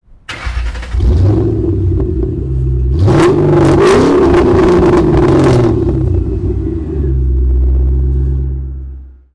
Mustang Exhaust Ringtone
Category car-engine-sounds